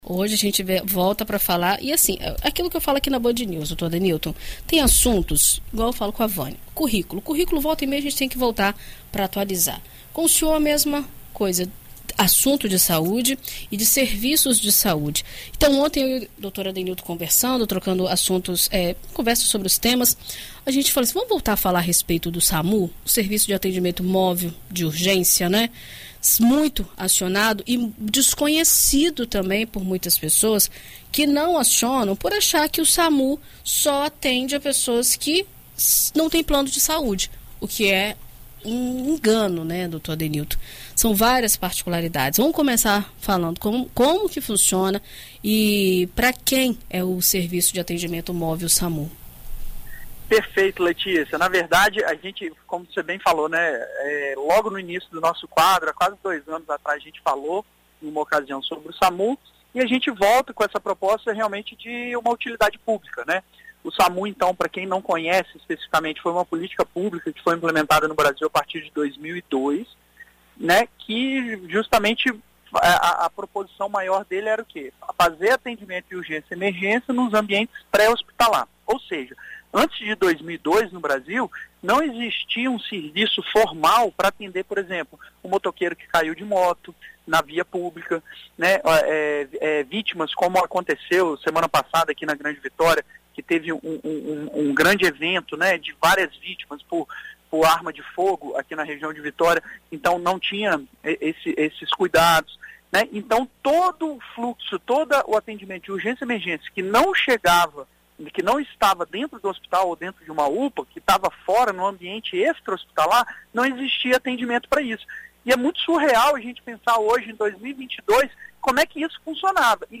Visita Médica: especialista explica quando o SAMU deve ser acionado